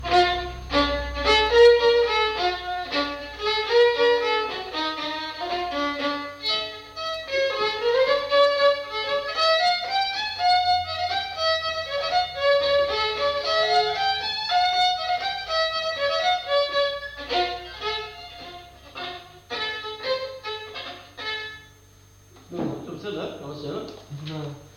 Lieu : Roquefort
Genre : morceau instrumental
Instrument de musique : violon
Danse : polka piquée